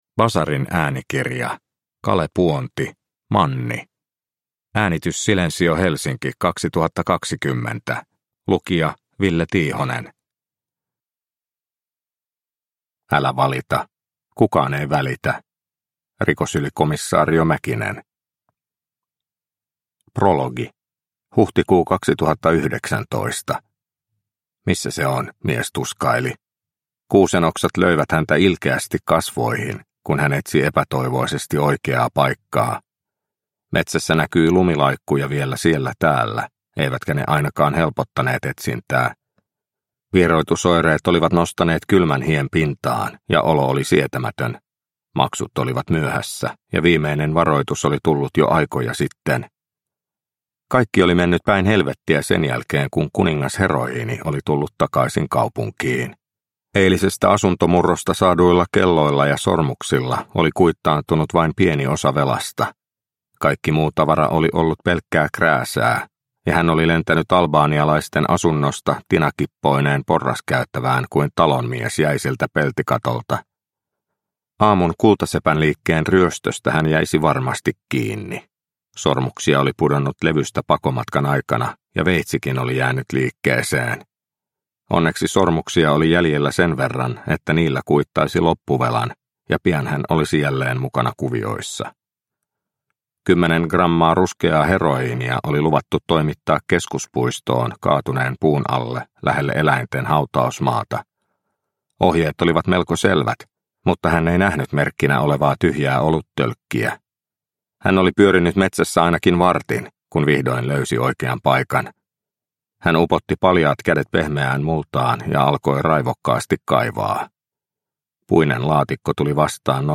Manni – Ljudbok – Laddas ner